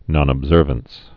(nŏnəb-zûrvəns)